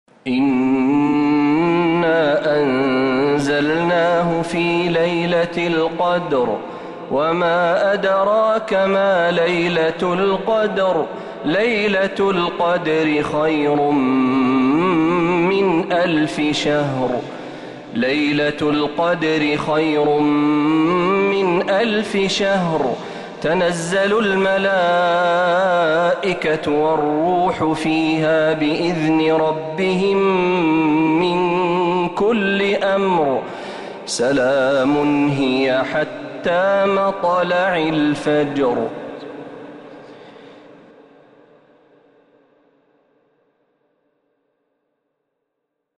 سورة القدر كاملة من الحرم النبوي